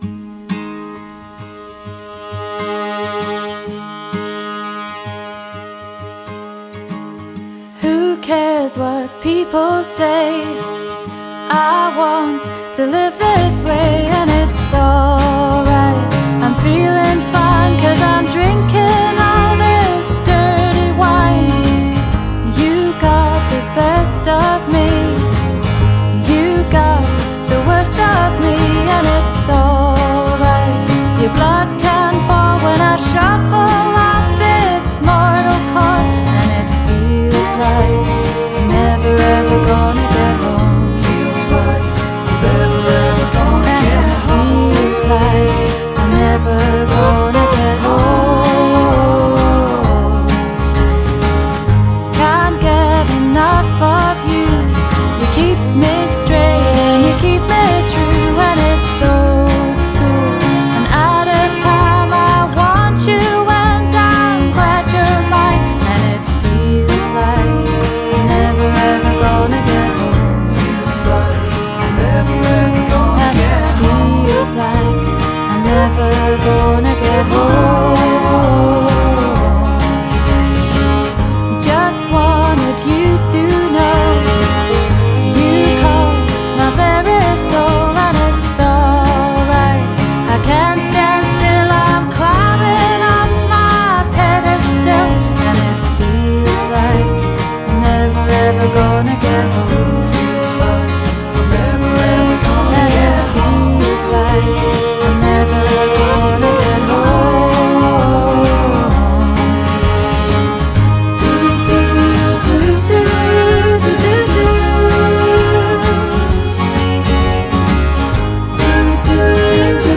has a lovely traditional pop-folk feel
signature TF harmonies are still there